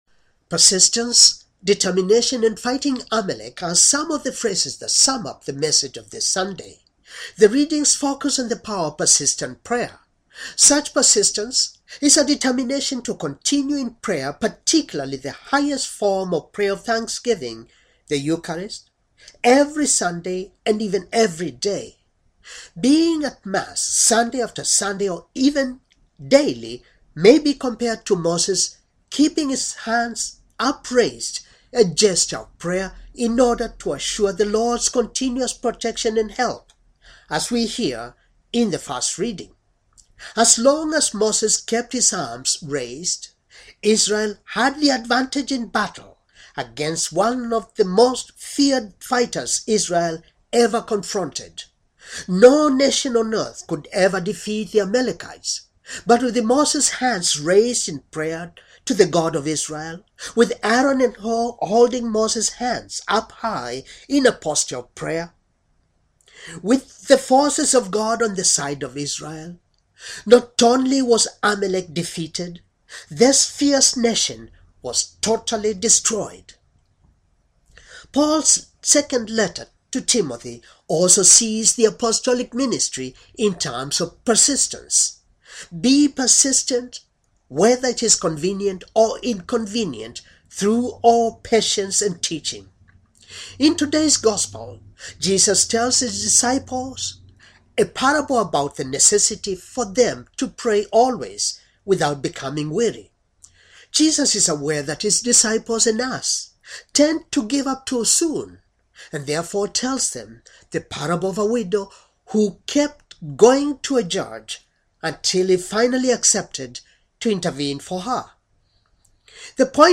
Homily for, 29th, Sunday, ordinary time, year c